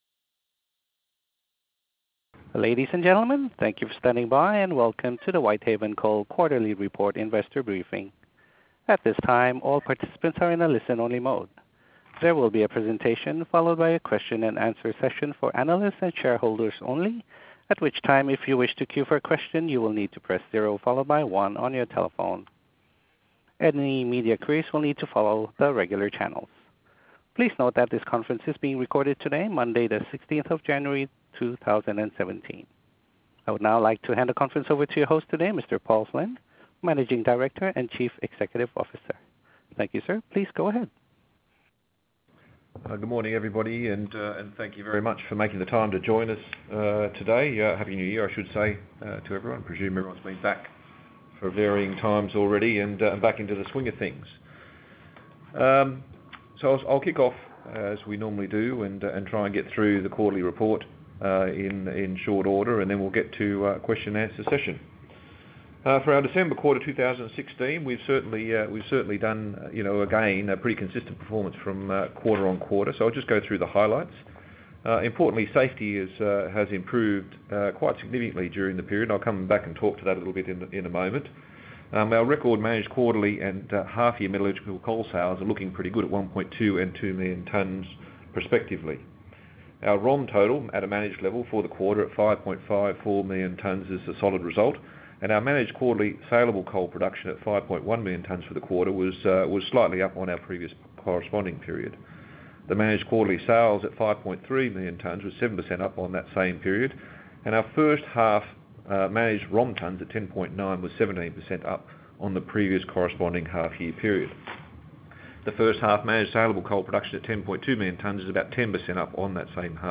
The audio of the Investor Call regarding this report can be found below: